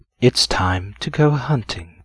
vs_fScarabx_attk.wav